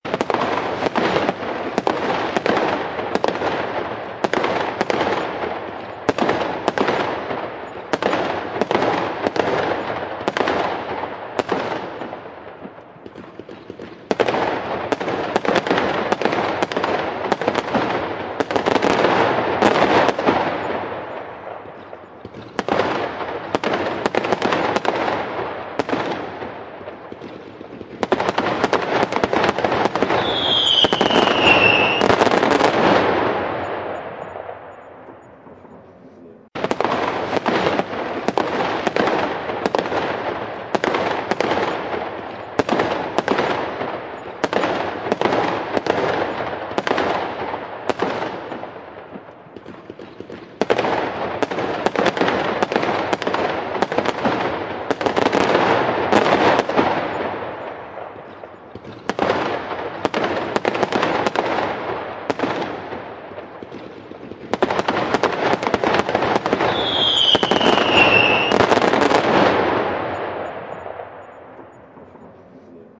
Fireworks Sound Effect Free Download
Fireworks